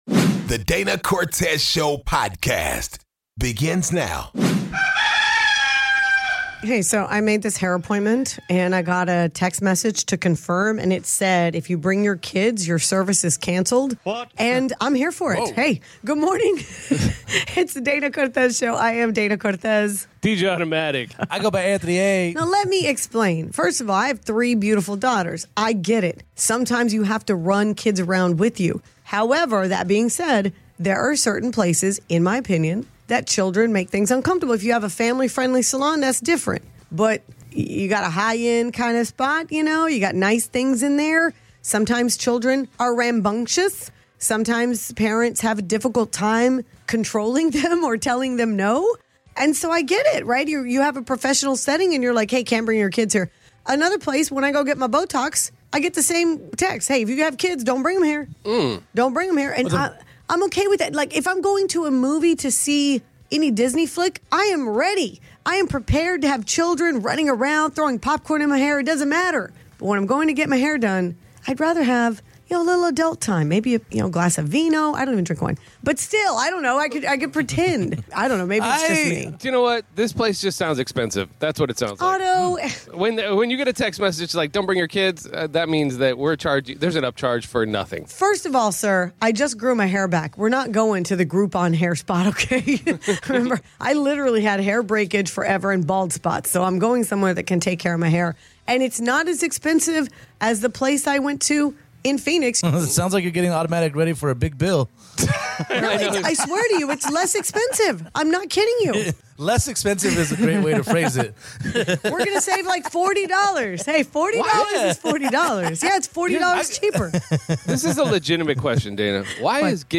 In today's Down in the DM breakdown DCS talks to listeners about how they hooked up and debate wether its best to have love at first site or should you be friends first.